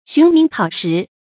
循名考实 xún míng kǎo shí
循名考实发音